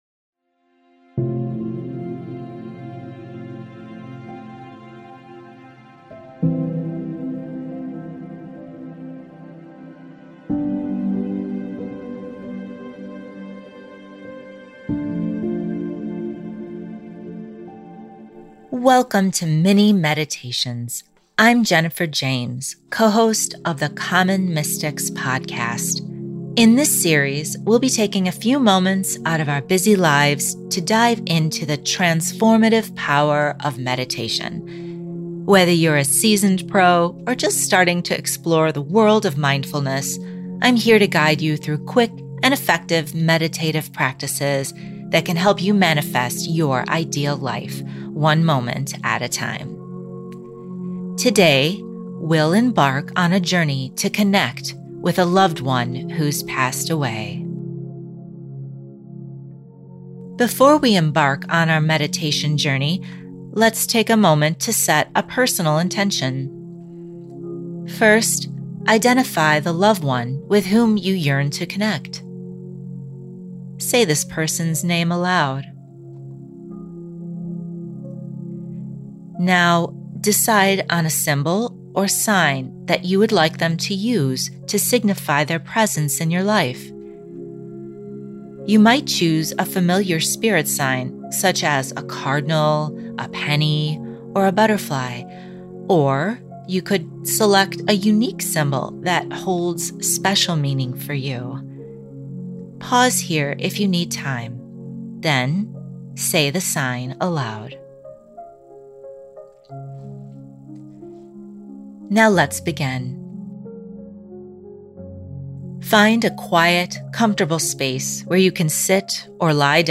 Common Mystics' Mini Meditation for Connecting to Loved Ones Who've Passed Away